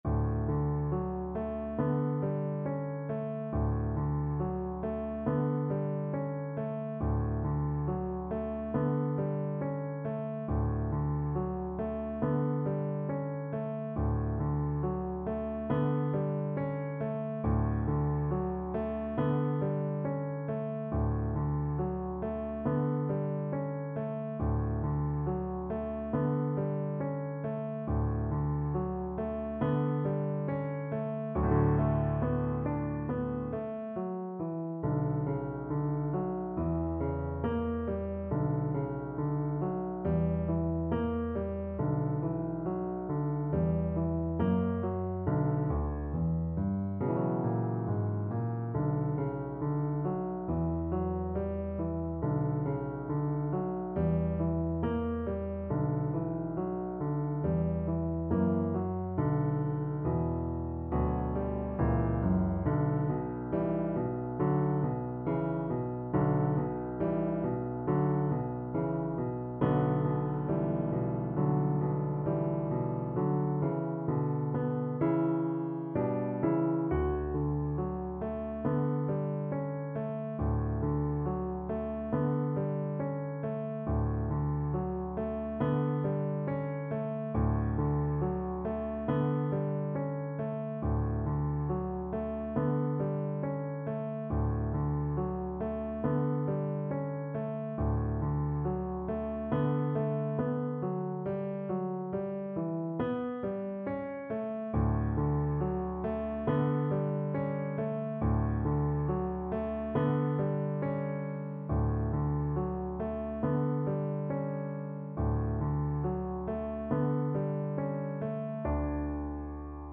4/4 (View more 4/4 Music)
D major (Sounding Pitch) (View more D major Music for Cello )
~ =69 Poco andante
Classical (View more Classical Cello Music)